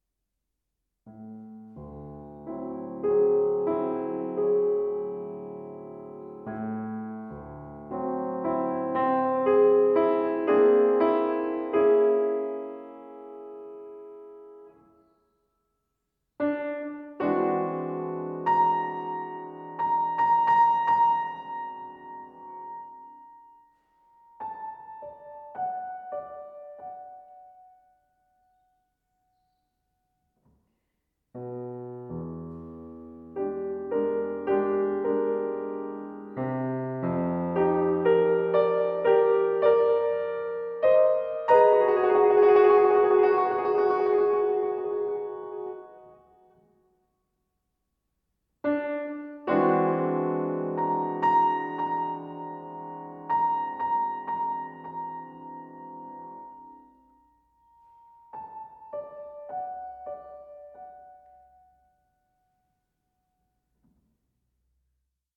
for solo piano
piano